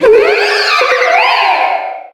b79f4b2f9323f43ad558f246b0a5ff07420cc285 infinitefusion-e18 / Audio / SE / Cries / LOPUNNY_1.ogg infinitefusion 57165b6cbf 6.0 release 2023-11-12 15:37:12 -05:00 20 KiB Raw History